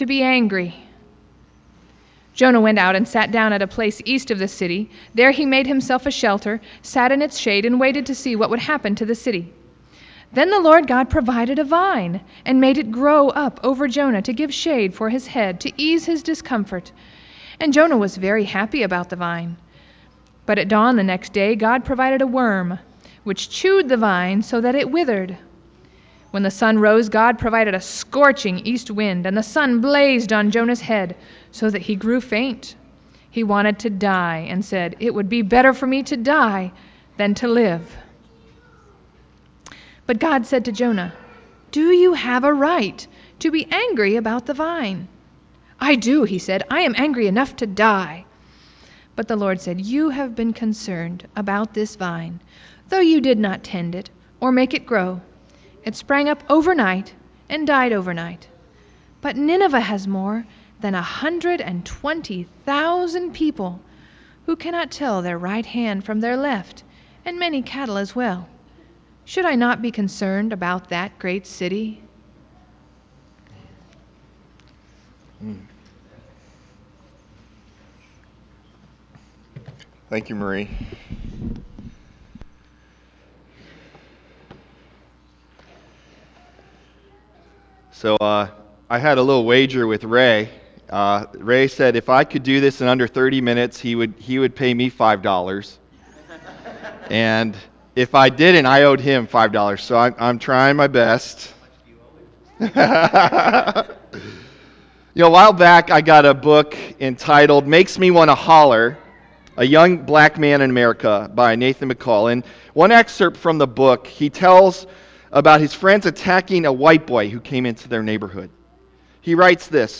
March 12 Sermon | A People For God